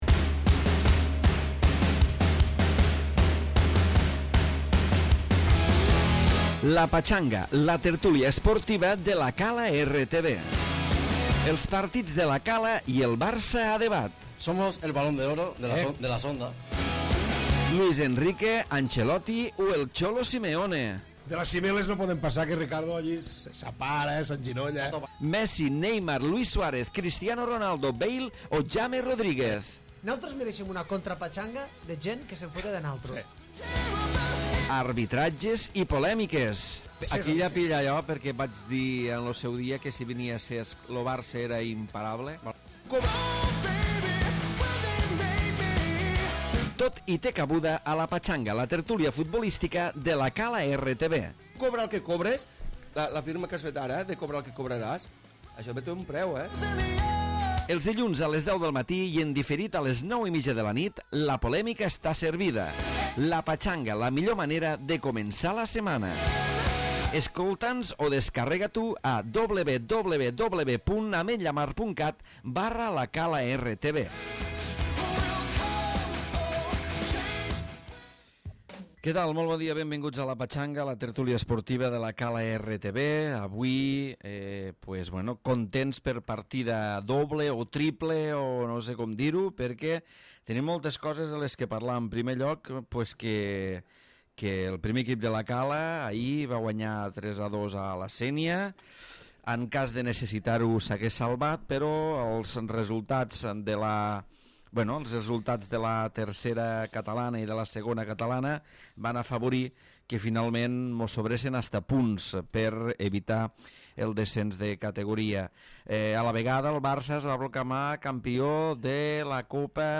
Tertúlia esportiva, on hem celebrat la victòria del Barça a la Copa del Rei, la salvació del 1er equip de La Cala i l'ascens del Gimnàstic de Tarragona